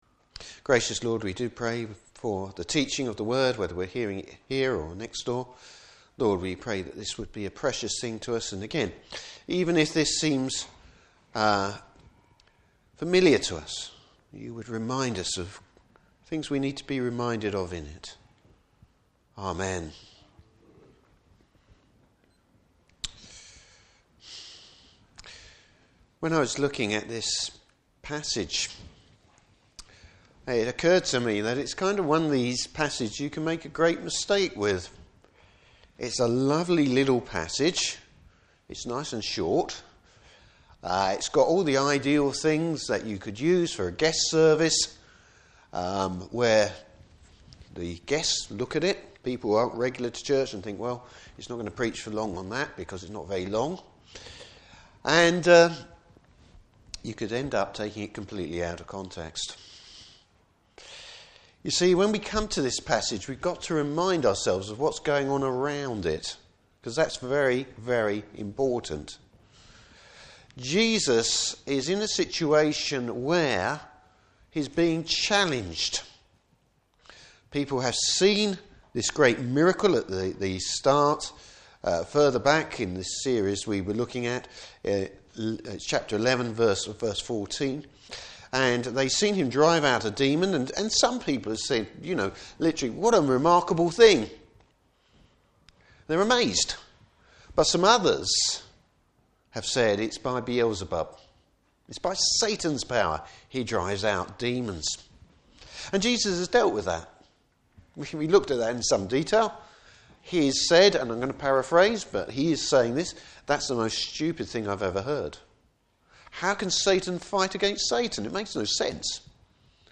Service Type: Morning Service Bible Text: Luke 11:33-36.